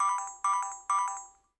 annnounce.wav